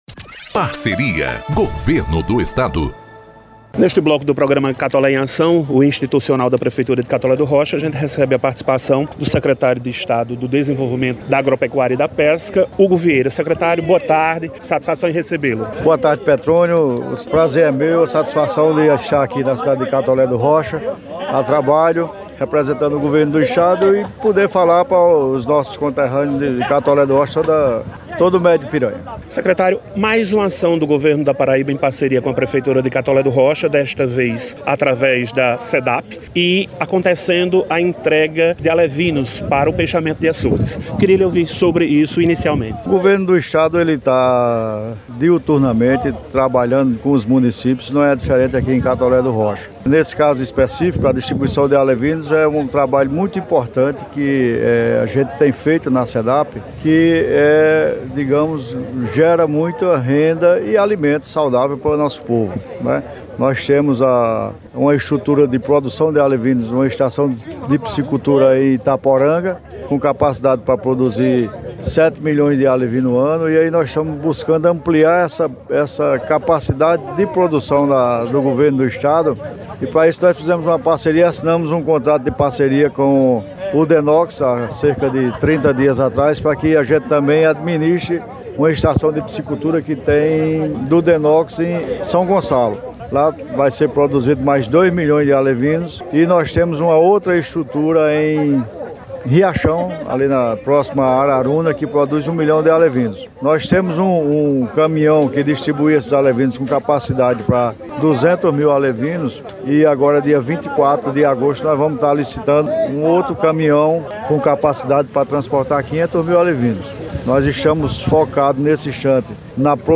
08-ENTREVISTA-Sec.-Estadual-Hugo-Vieira-SEDAP.mp3